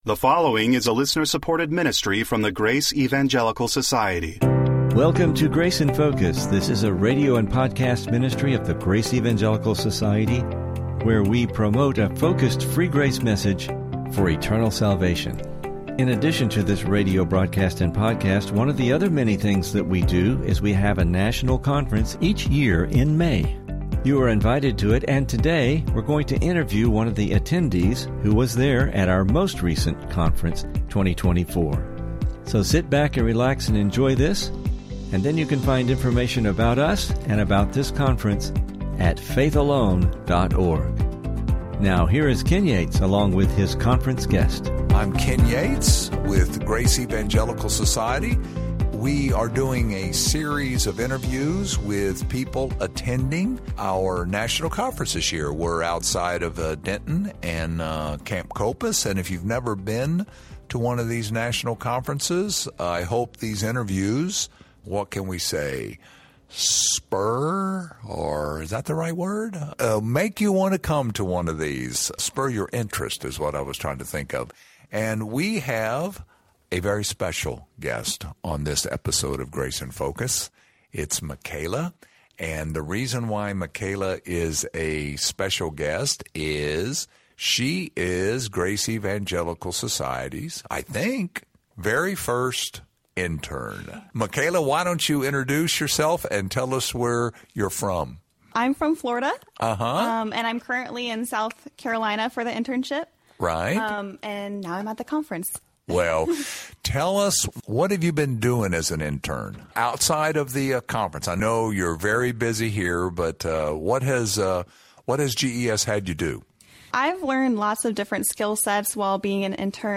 Interview – GES National Conference 2024